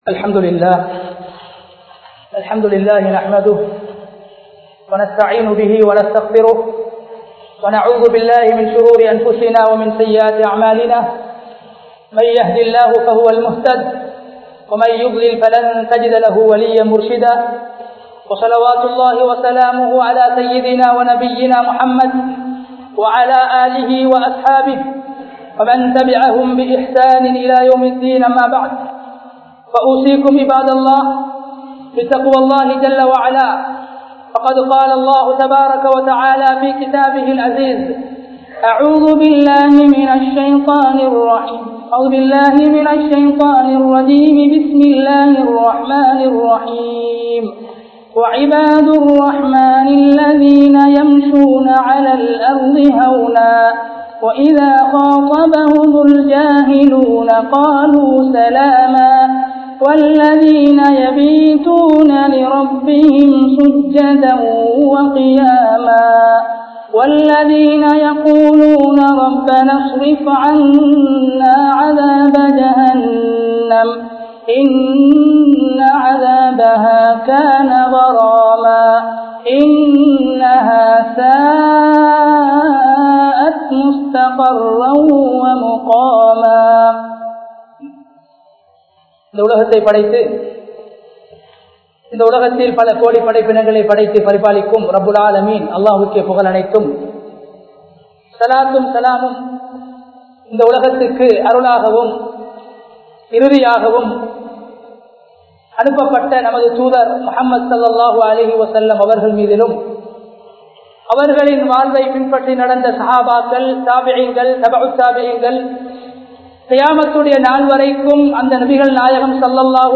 Allahvin Adimaihal (அல்லாஹ்வின் அடிமைகள்) | Audio Bayans | All Ceylon Muslim Youth Community | Addalaichenai